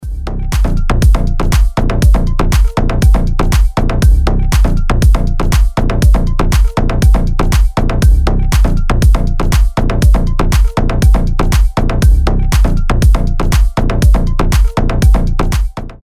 ритмичные
громкие
EDM
без слов
house
динамичные
Просто динамичная хаус музыка